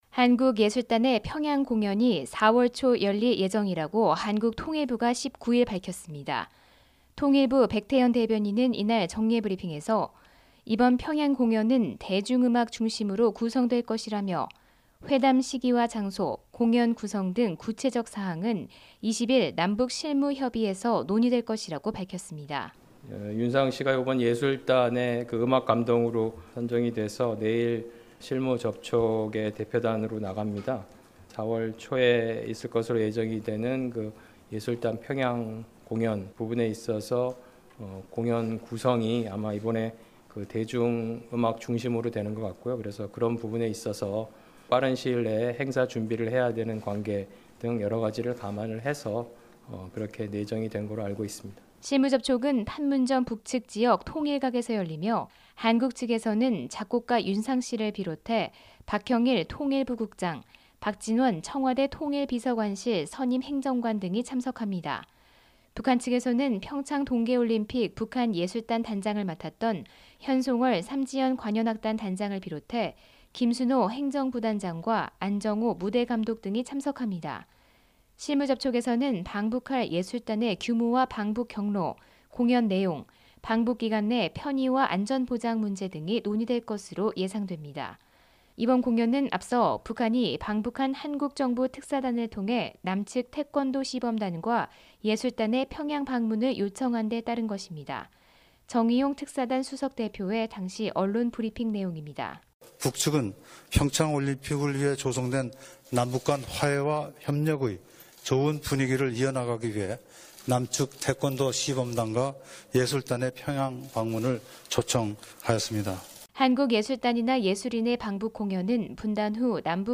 [특파원 리포트 오디오] 한국 예술단 4월 초 평양 공연…20일 실무접촉